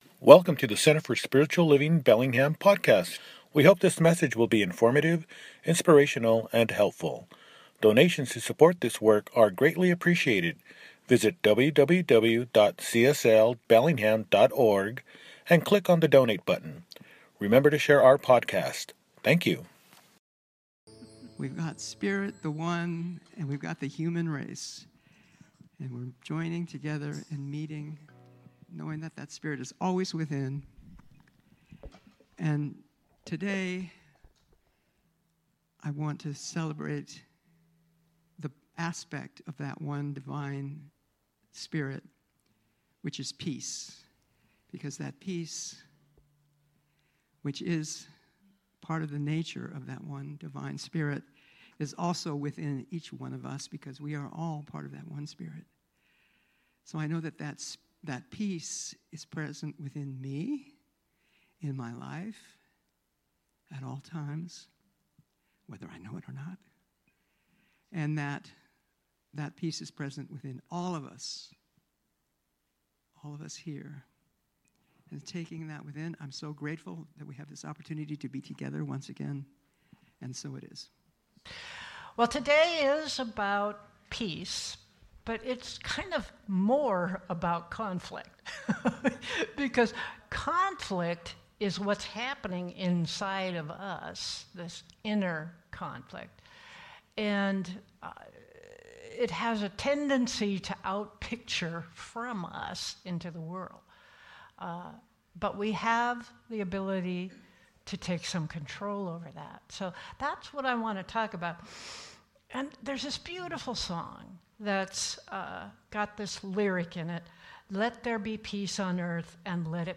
Transforming Conflict within Ourselves and our World– Celebration Service | Center for Spiritual Living Bellingham